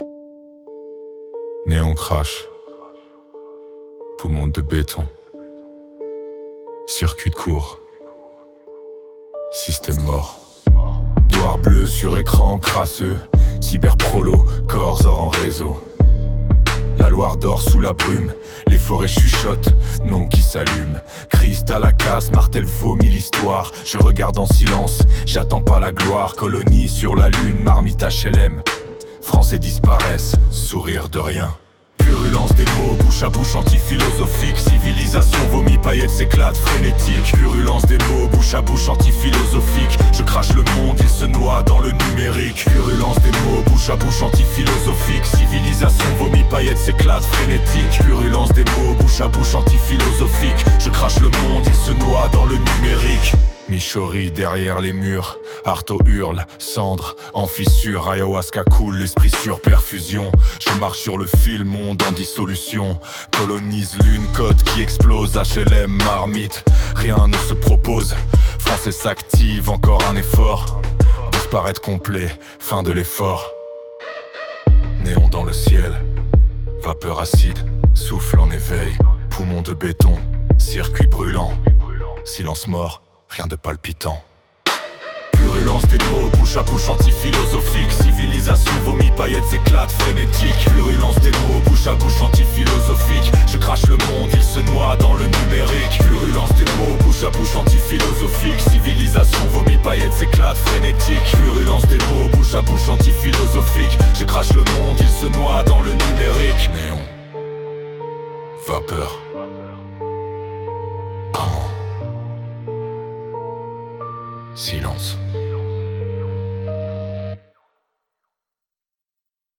12:09 Publié dans Impertinences, Survie | Lien permanent | Commentaires (0) | Tags : rap